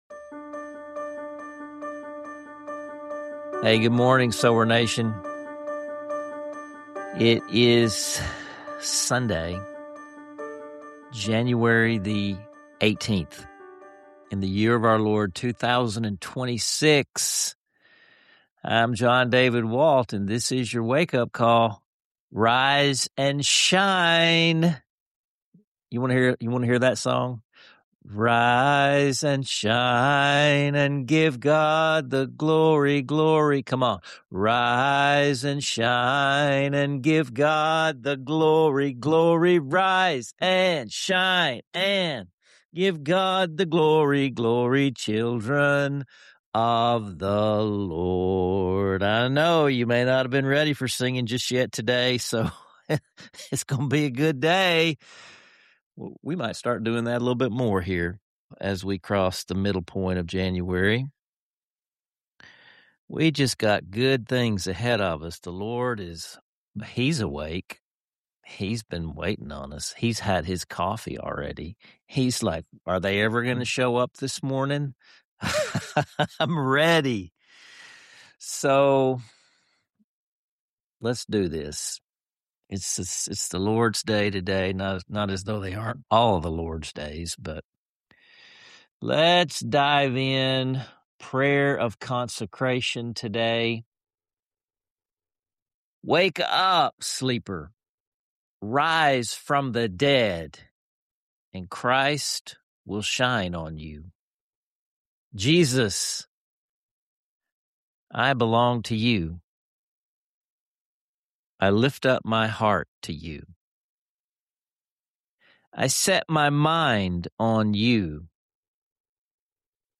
Through joyful singing, heartfelt prayers, and honest conversation